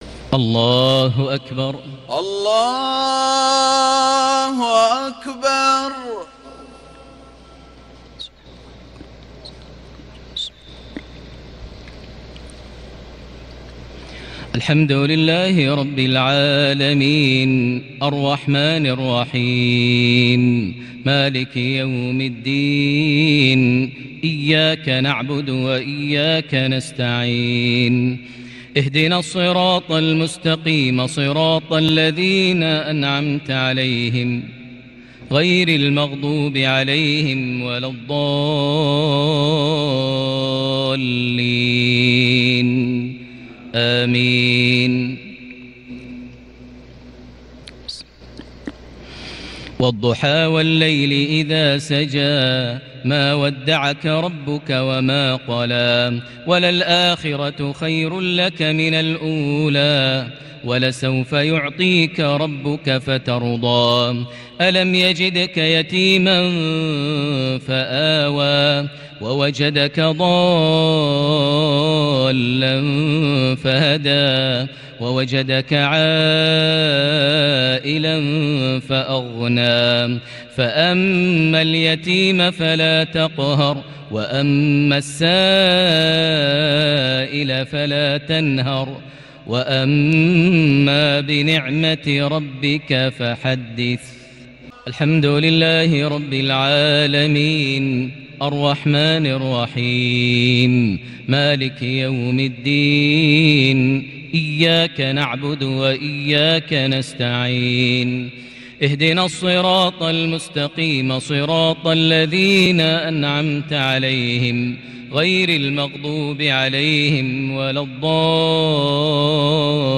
صلاة الجمعة تلاوة بديعة لسورتي الضحى - الشرح | 13 شعبان 1442هـ > 1442 هـ > الفروض - تلاوات ماهر المعيقلي